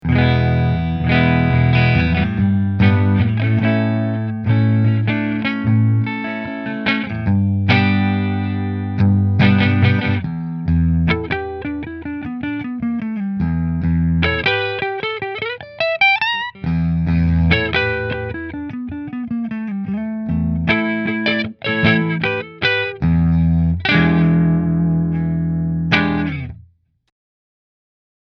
• Mahogany Hollow Body with AAA Quilt Maple Top
• 2 Seymour Duncan SH-1 ’59 Pickups
Prestige Heritage Hollow SB QM Neck Through Fender